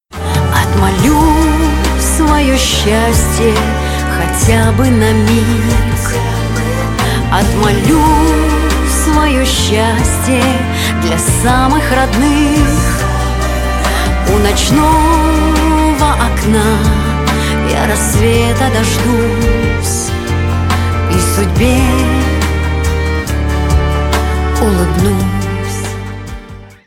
Нарезка припева на вызов